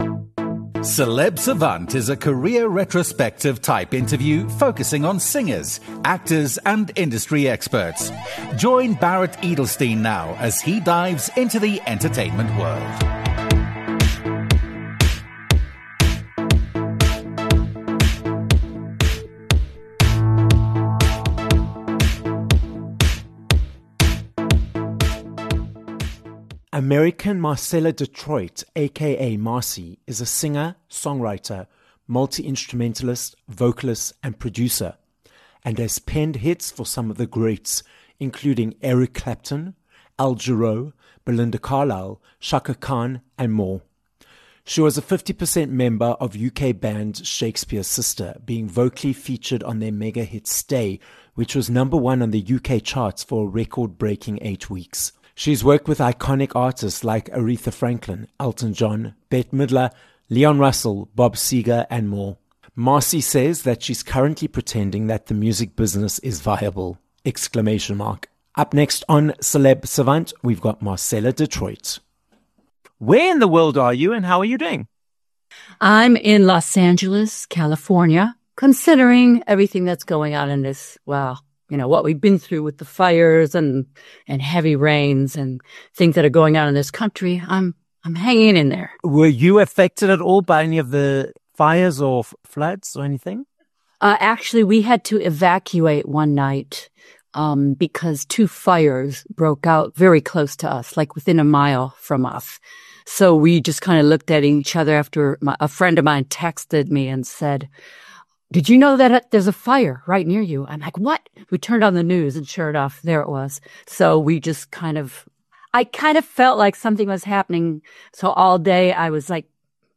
Marcella Detroit - an American singer, songwriter, multi-instrumentalist, and producer - joins us on this episode of Celeb Savant.